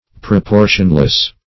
Proportionless \Pro*por"tion*less\, a. Without proportion; unsymmetrical.